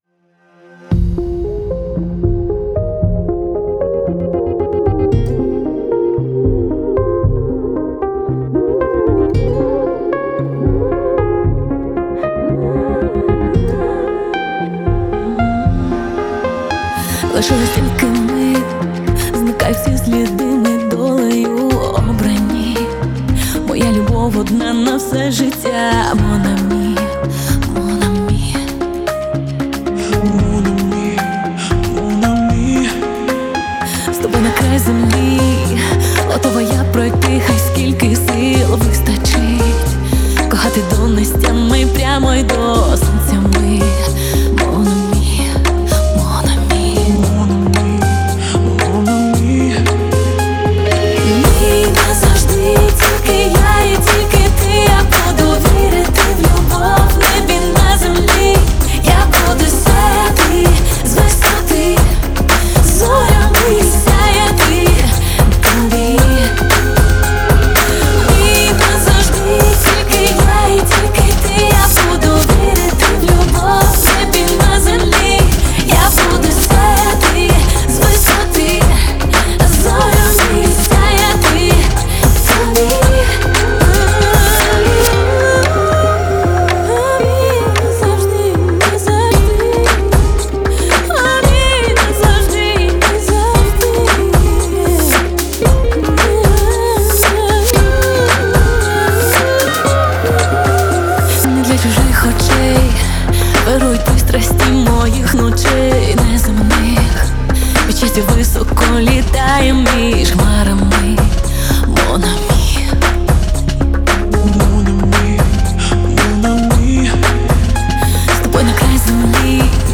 это проникновенная поп-баллада
мощный вокал и мелодичное звучание